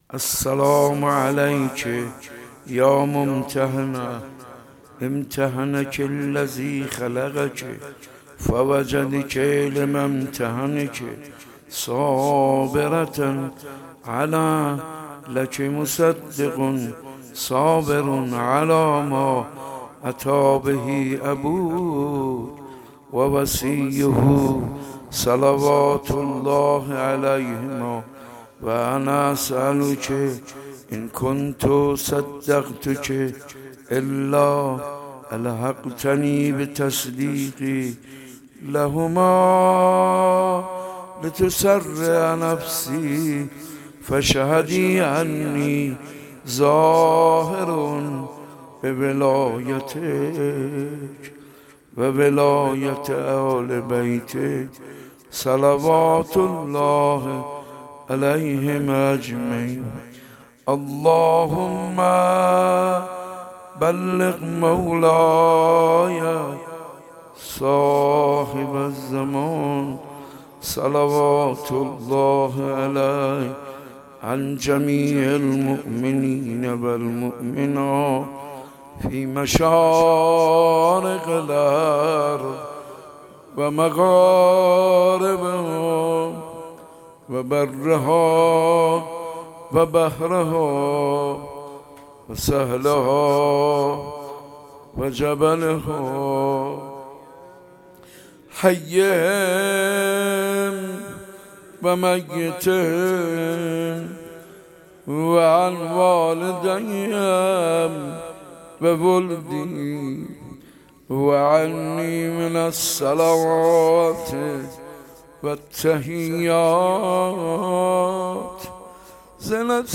صوت/ روضه سوزناک حاج منصور برای حضرت زهرا
روضه شهادت حضرت زهرا(س) با نوای حاج منصور ارضی را در ادامه می توانید دریافت نمایید.
ایام فاطمیه شهادت مداحی عاشورا حاج منصور ارضی مداح اهل بیت